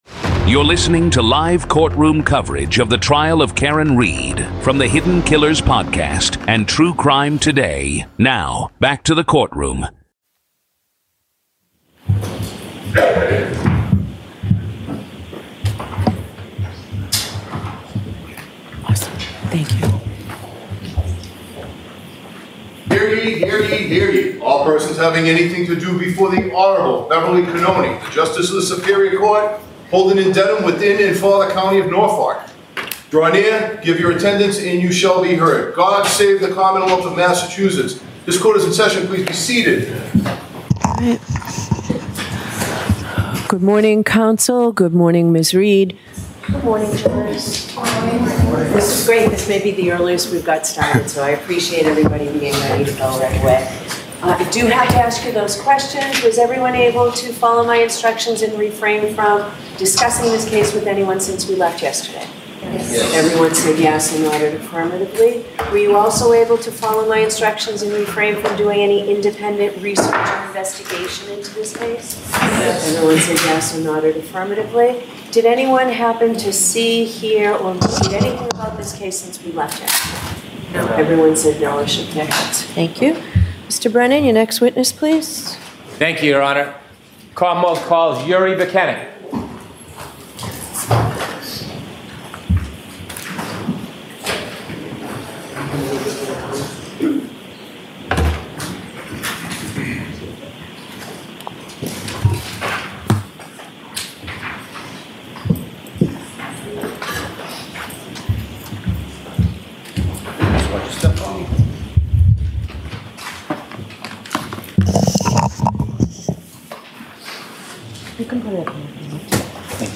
This is audio from the courtroom in the high-profile murder retrial of Karen Read in Dedham, Massachusetts. She's facing second-degree murder charges and more in connection with the death of her boyfriend, Boston Police Officer John O’Keefe.